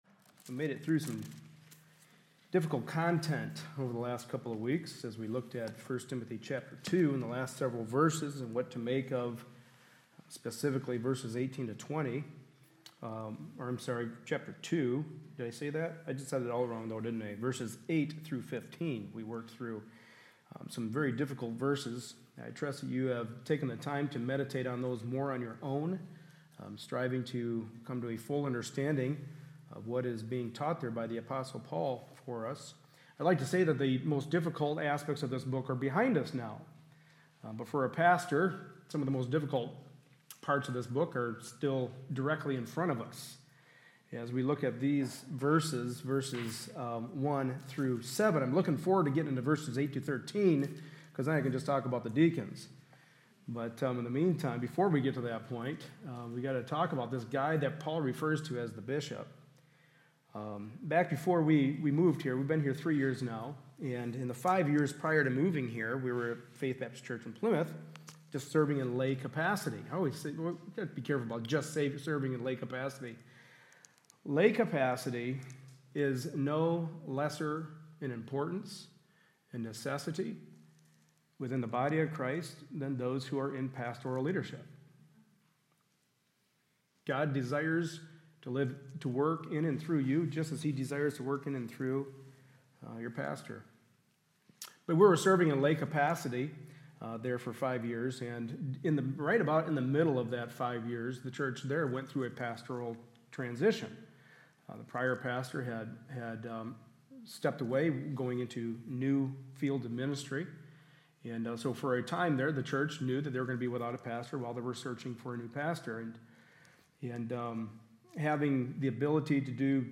Service Type: Sunday Morning Service A study in the pastoral epistles.